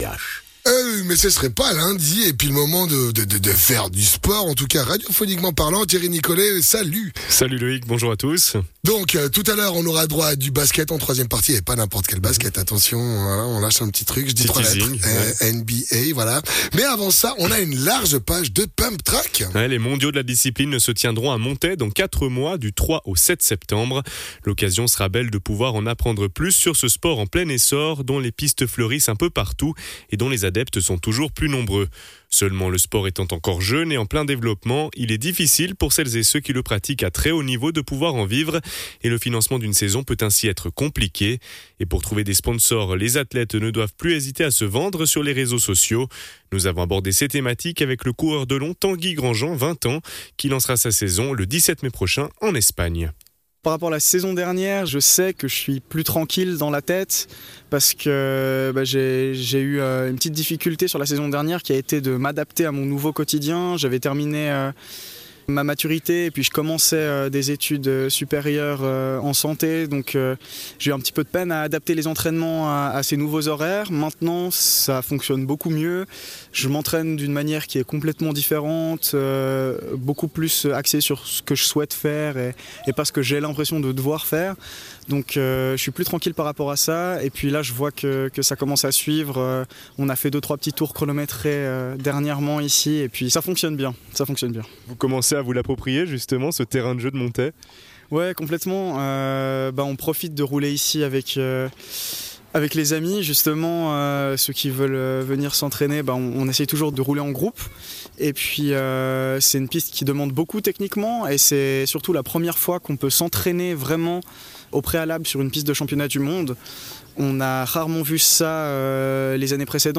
spécialiste de pumptrack